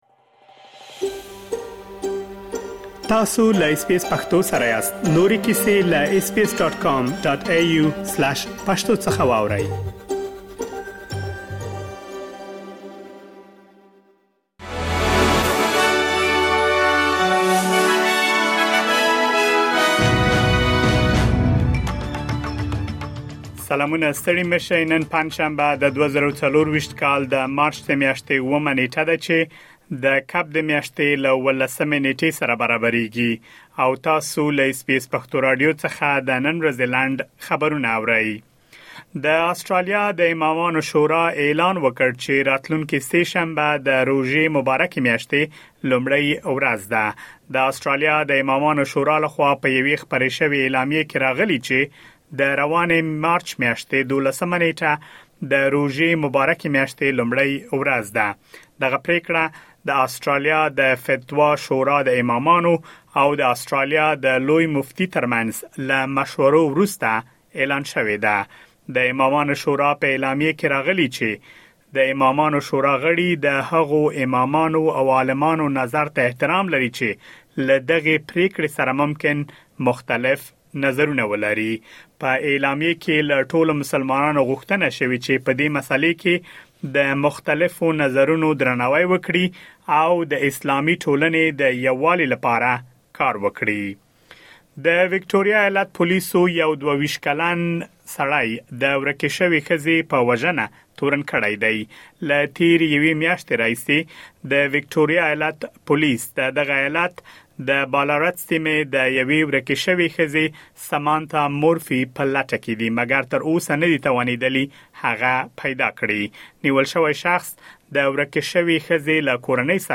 د اس بي اس پښتو راډیو د نن ورځې لنډ خبرونه|۷ مارچ ۲۰۲۴